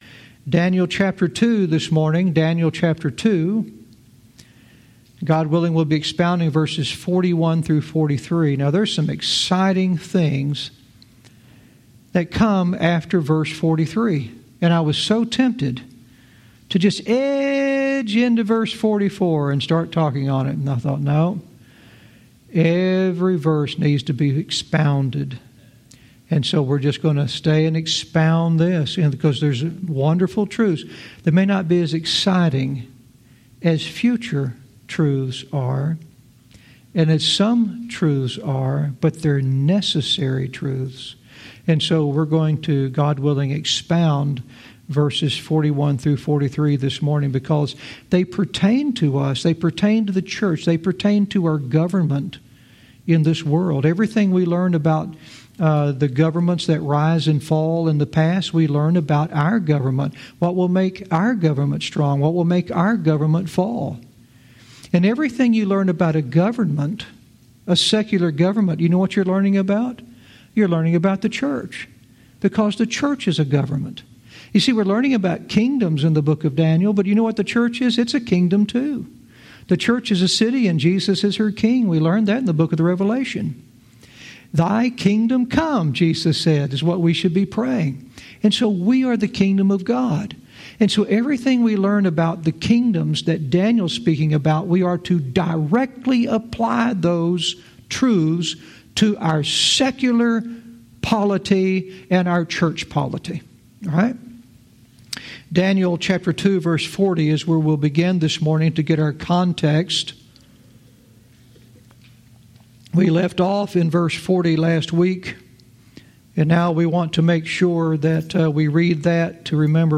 Verse by verse teaching - Daniel 2:41-43 "Mingled Men"